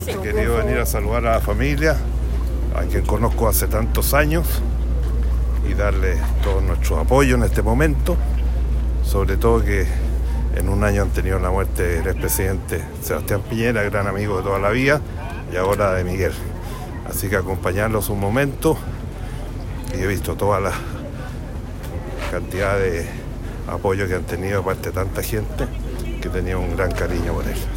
Una vez en el cementerio Parque del Recuerdo, fue velado en presencia de distintas personalidades públicas, quienes acudieron a despedirlo. Entre ellas, se encontraba el expresidente de la República, Eduardo Frei Ruiz-Tagle, quien prestó declaraciones al respecto.